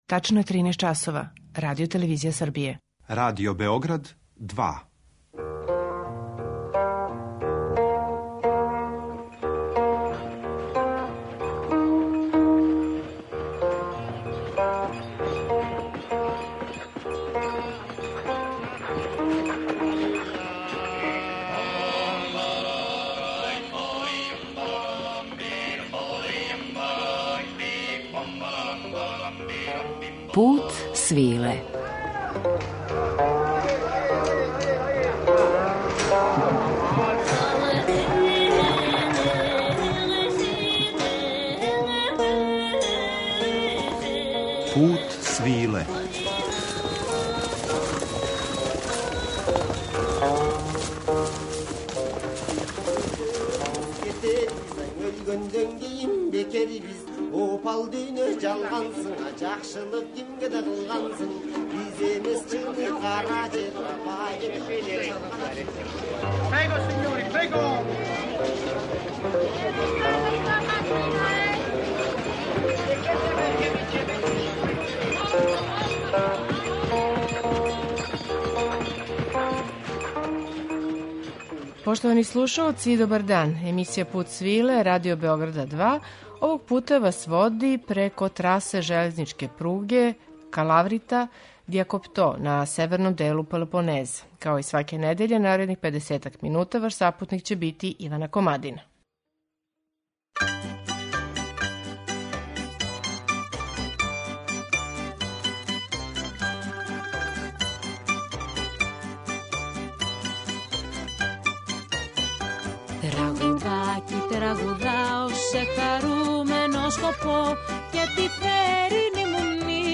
Користио је старе народне напеве и плесове и комбиновао их са стиховима који су потпуно савремени и неретко политички ангажовани.